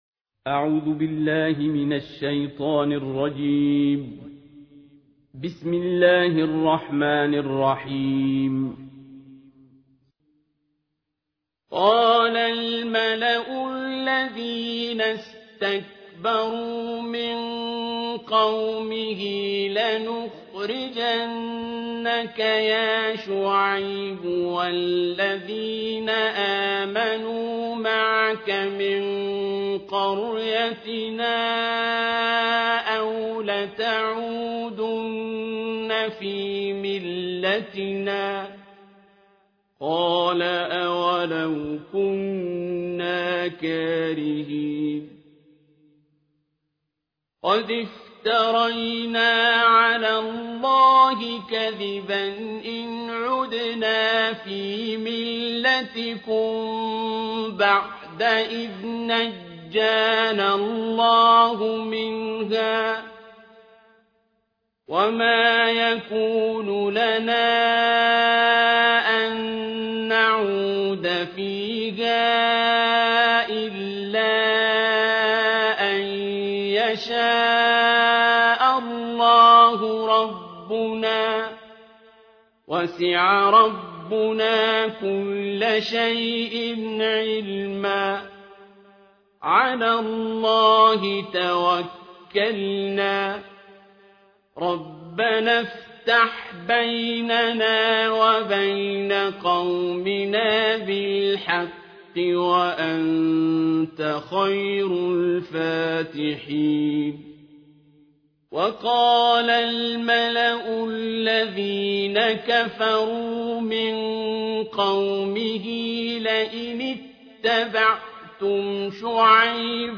تلاوت جزء نهم قرآن کریم با نوای استاد عبدالباسط(متن و ترجمه)